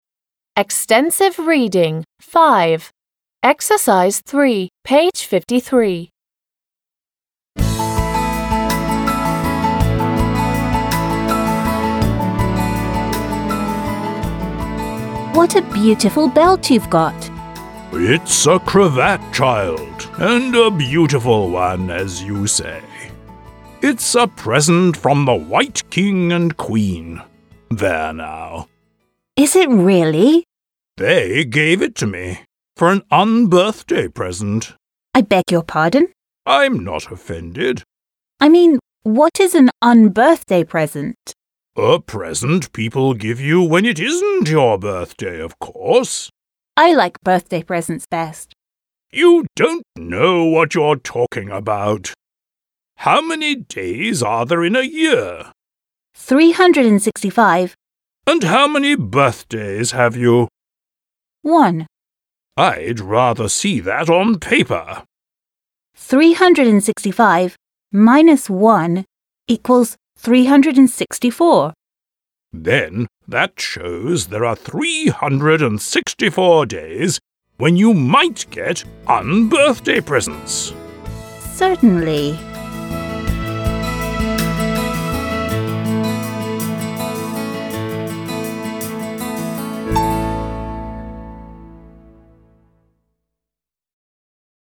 The extract is a short dialogue from the second book. Alice is talking to Humpty Dumpty, an egg with a nose and a mouth!